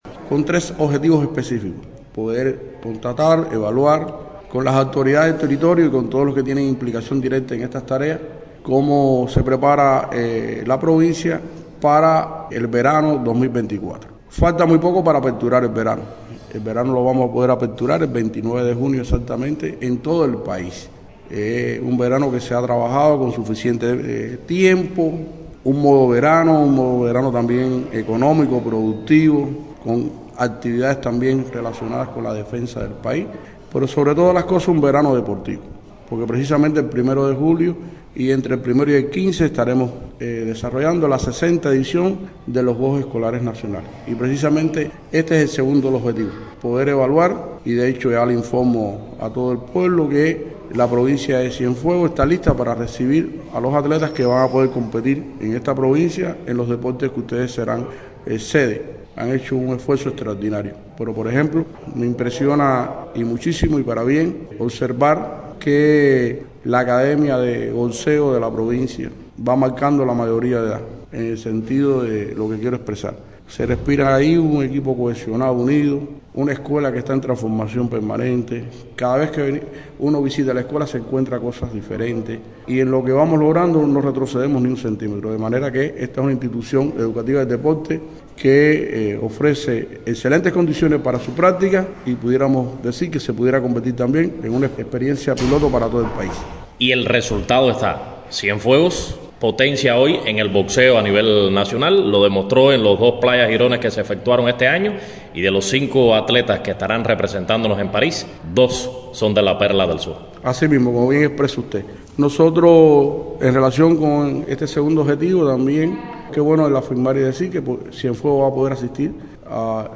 Osvaldo Vento Montiller, presidente del INDER, en visita reciente a Cienfuegos conversó con Radio Ciudad del Mar sobre varios temas: el verano, la edición 60 de los Juegos Escolares Nacionales y los Juegos Olímpicos de París.